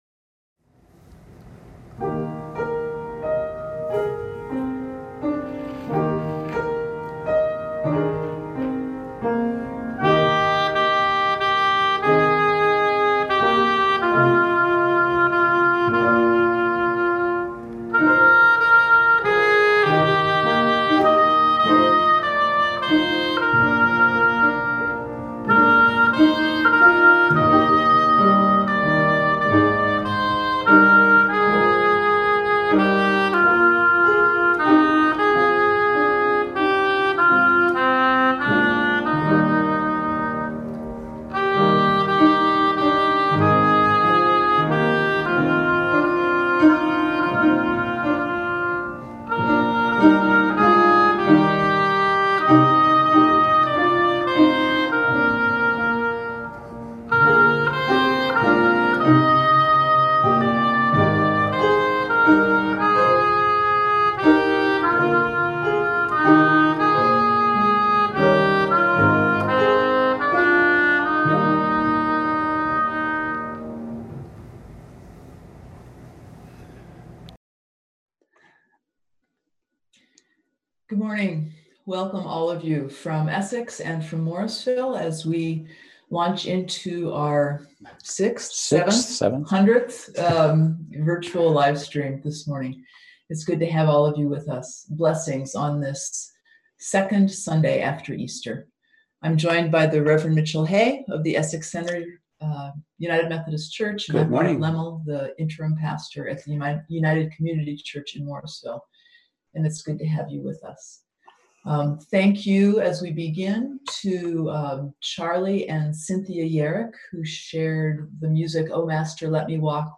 We held virtual worship on Sunday, April 26, 2020 at 10am.